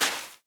PixelPerfectionCE/assets/minecraft/sounds/step/sand2.ogg at mc116
sand2.ogg